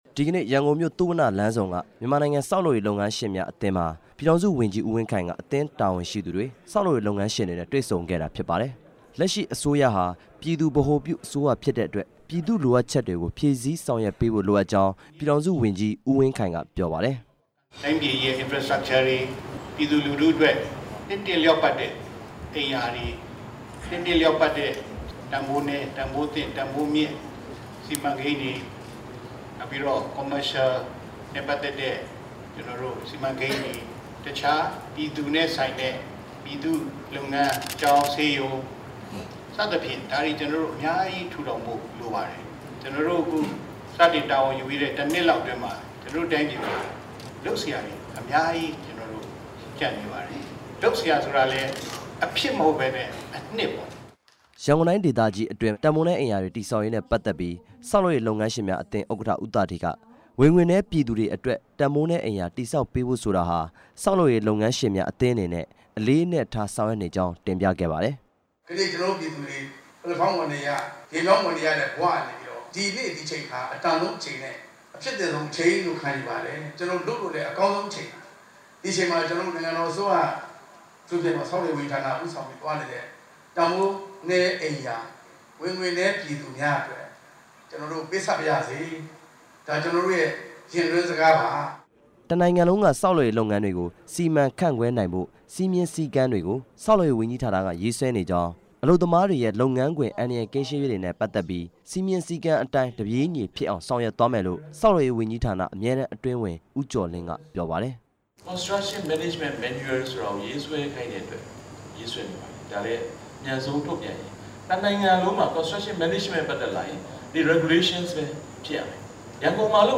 ဆောက်လုပ်ရေးဝန်ကြီးဌာန သတင်းစာရှင်းလင်းပွဲ